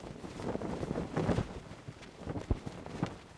cloth_sail11.R.wav